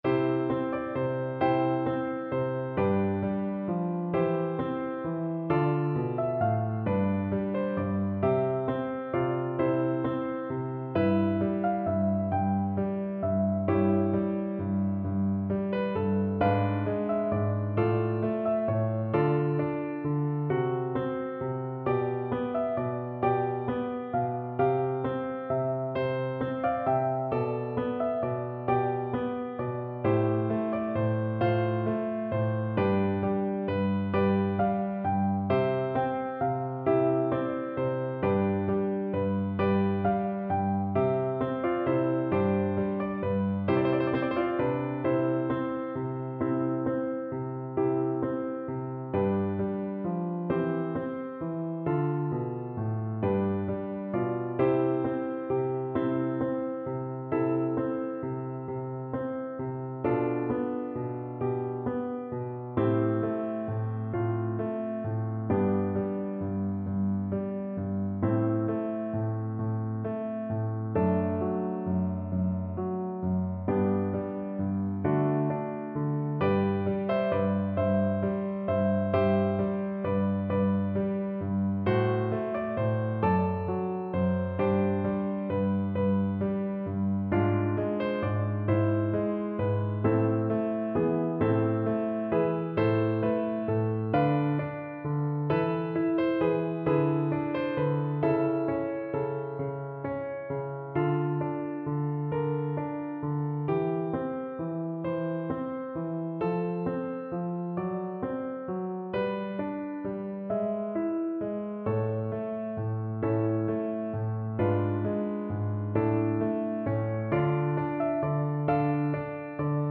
. = 44 Largo
12/8 (View more 12/8 Music)
F5-F6